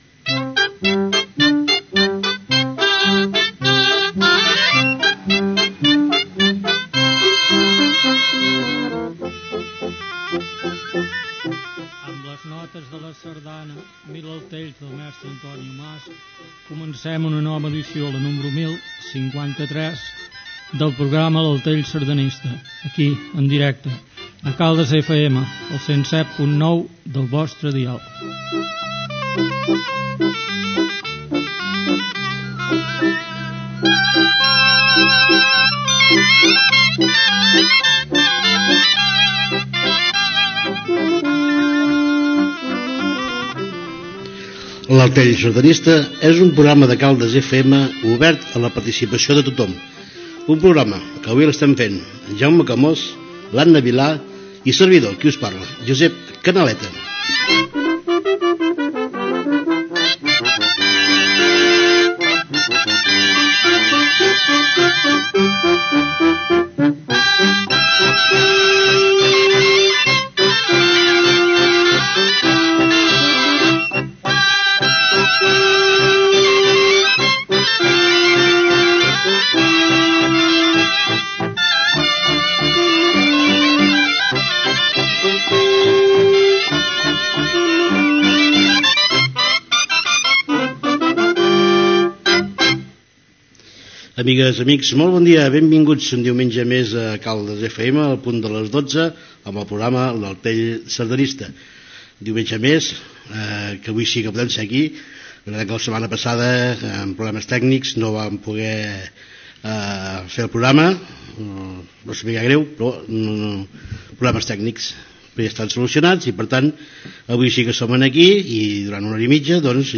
Sintonia, inici del programa 1053 dedicat al món sardanístic, Ballada a Sant Feliu de Guíxols
Musical